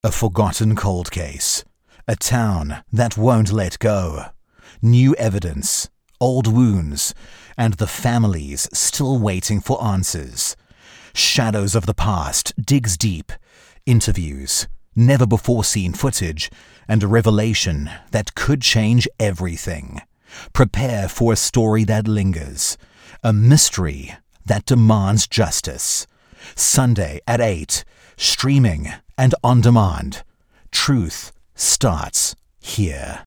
Filmtrailer
Herzlich, sanft und vielseitig.